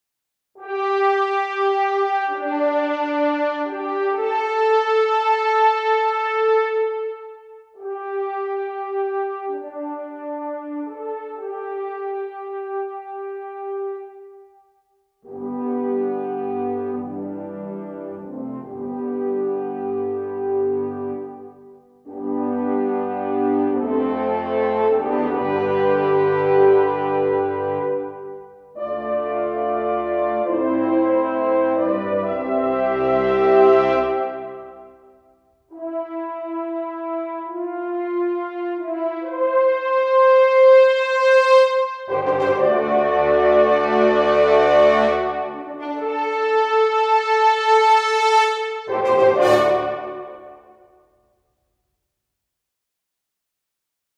But here is a rough, grungy try with Jupiter's and Zodiac's horns:
surmises and guesses right regarding your excellent test piece for huge horns
The opening melodic passage is Zodiac, playing the first 4 notes at (or very near) max dynamic; then, much more softly, a melodic variant: G, D, A, G.
Here, Jupiter's horns sound somewhat congested - which is to be expected for 24 horns playing chords, especially when in equal temperament.
Then Jupiter hands back to Zodiac after a nice big swell (up to max dynamic? or nearly so) on a big fat open C chord.
After that fearsome show of might, Jupiter jumps back in to end the piece with a celebratory fanfare kind of flourish at max power, as if stealing Zodiac's thunder by saying "oh yeah we got the power!"